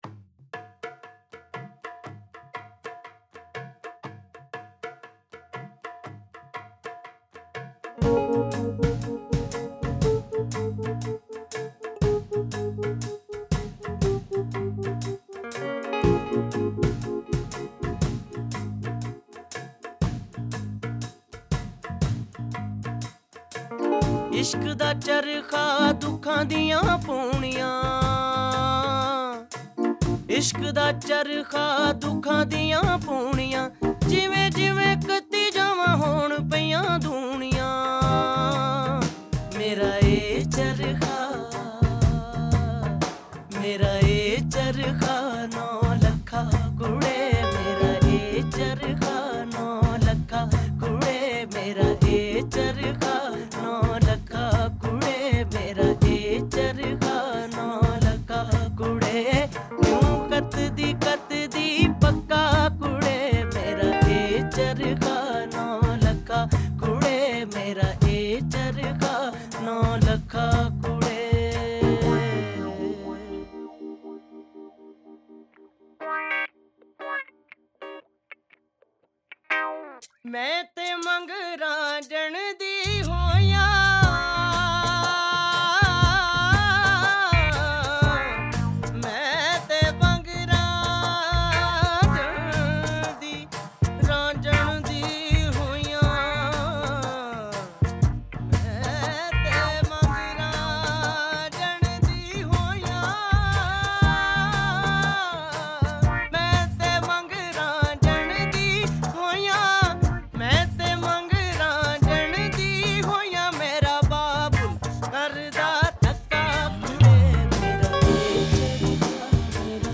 Punjabi Qawwali and Sufiana Kalam